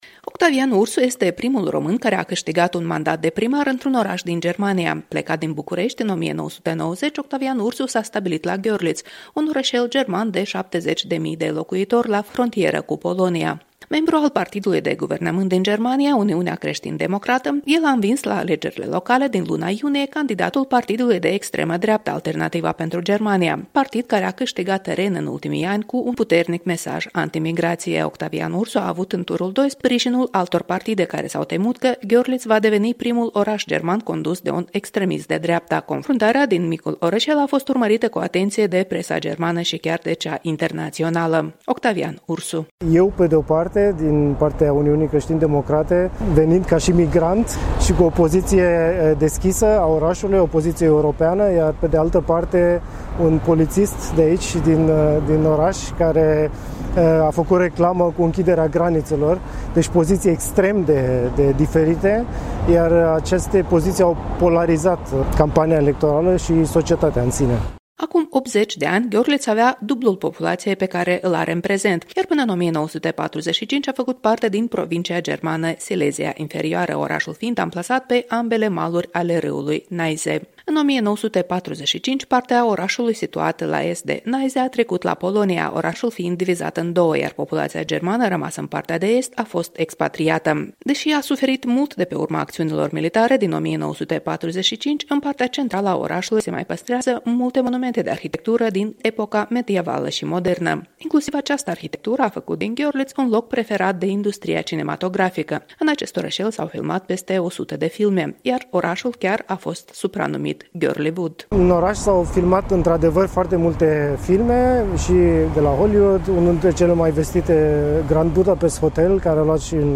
„Putem observa ce înseamnă 40, 45 de ani de socialism. A fost o mare pierdere la nivel economic în tot Estul Europei și să recuperăm aceste pierderi durează foarte mult, durează mai mult de o generație”, declară într-un interviu exclusiv cu Europa Liberă, Octavian Ursu, noul primar al orașului Görlitz din Germania.